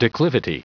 Prononciation du mot declivity en anglais (fichier audio)
declivity.wav